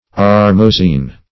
Search Result for " armozeen" : The Collaborative International Dictionary of English v.0.48: Armozeen \Ar`mo*zeen"\, Armozine \Ar`mo*zine"\, n. [armosin, armoisin.]